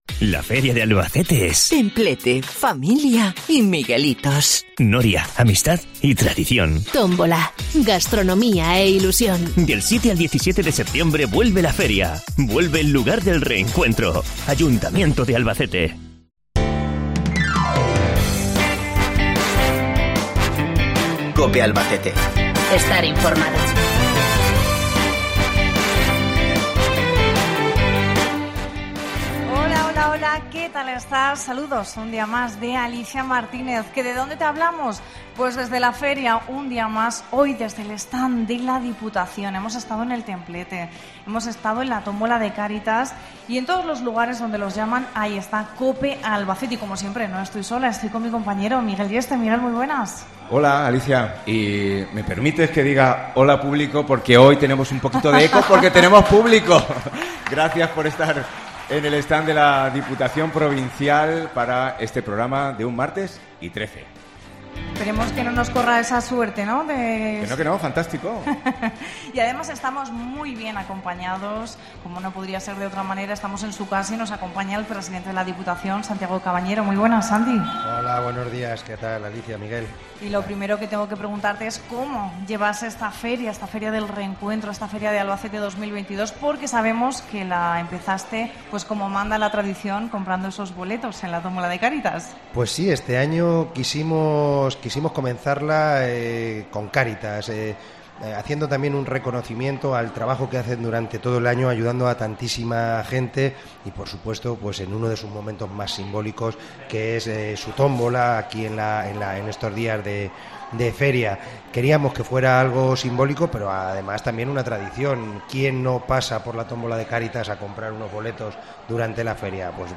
La nave viajera que COPE conduce esta Feria 2022 ha hecho parada este martes en el stand de la Diputación Provincial de Albacete. Dentro de su extensa programación, nuestro magazine diario ha sido un acto más en el que no han faltado invitados y público, en este caso de Casas de Juan Núñez, ya que este municipio ha sido hoy el protagonista de las presentaciones mañaneras.
Santiago Cabañero, el presidente de la institución provincial, ha sido nuestro anfitrión y primer entrevistado.